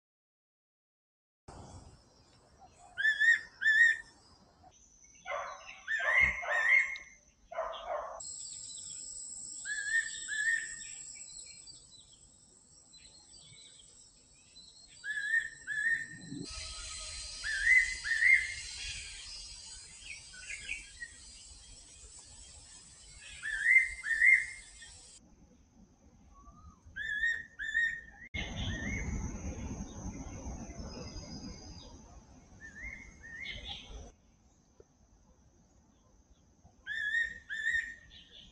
仙八色鸫鸟叫声